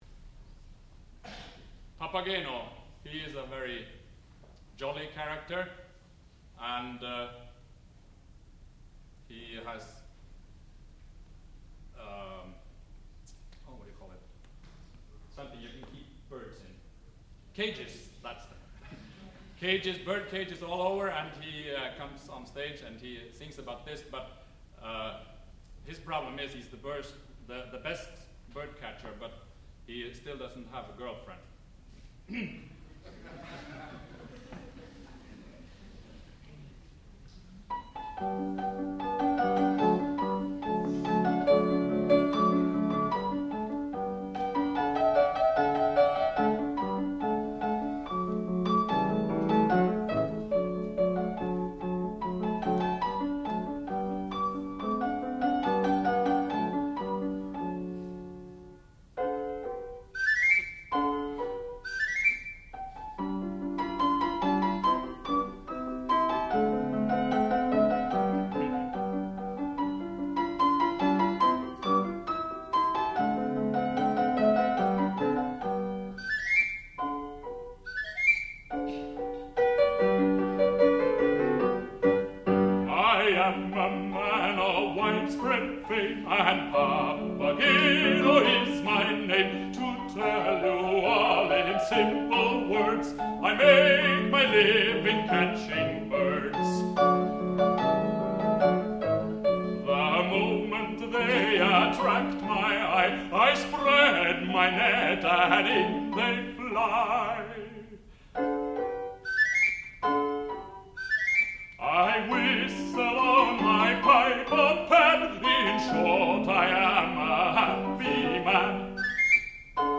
sound recording-musical
classical music
baritone
piano